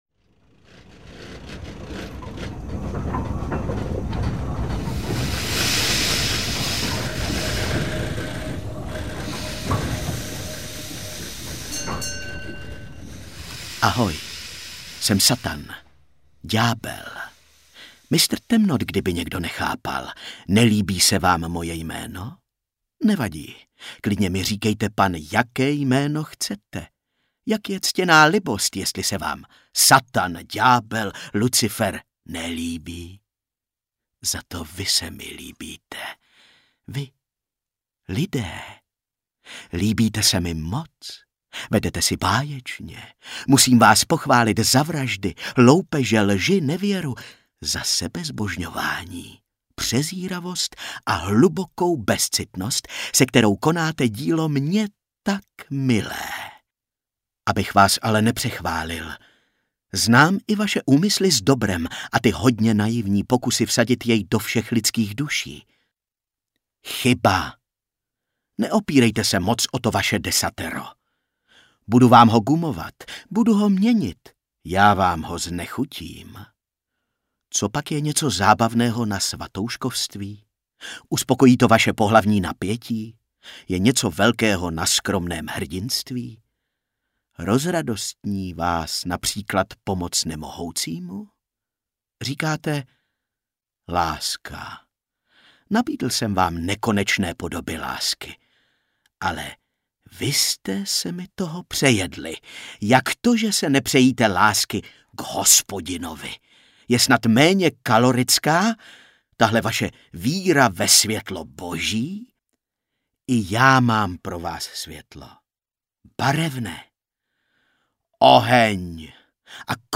Expres Inferno audiokniha
Ukázka z knihy